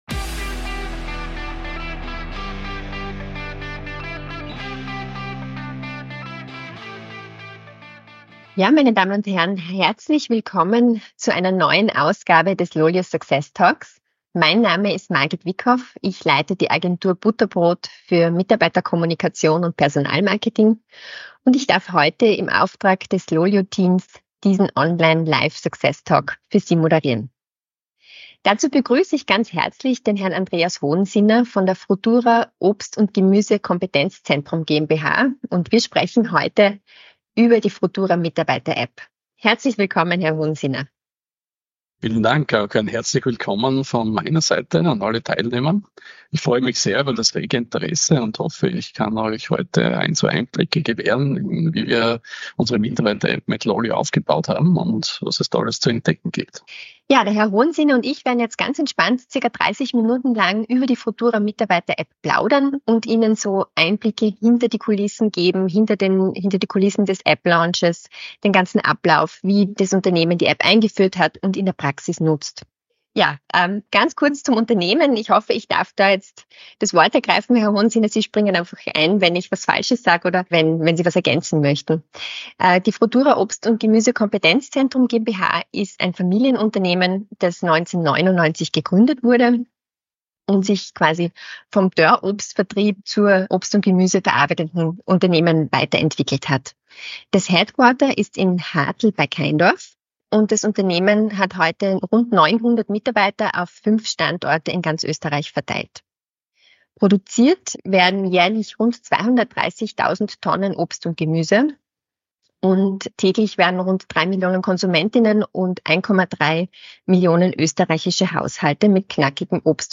Success Talks by LOLYO ist ein Podcast mit ausgewählten Talkgästen und spannenden Themen rund um die unternehmensinterne Kommunikation und Mitarbeiter-Apps.